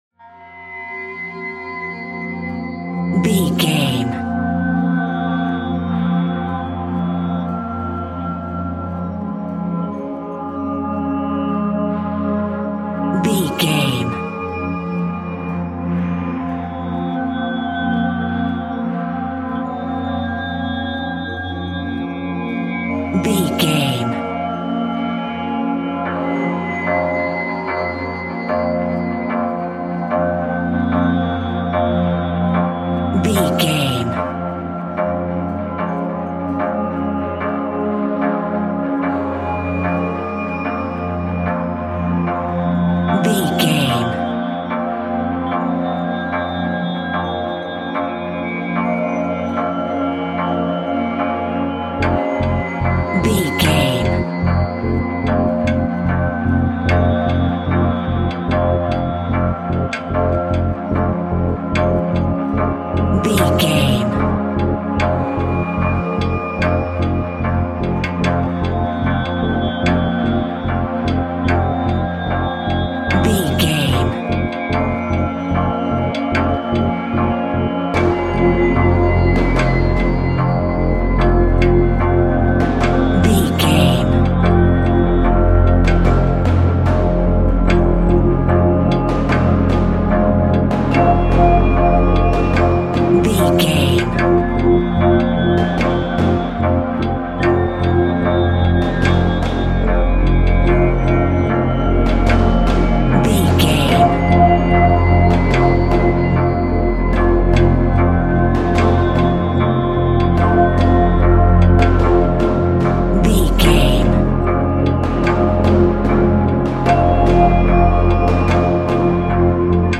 Aeolian/Minor
ambient
atmospheric
dissonant
eerie
ominous
suspenseful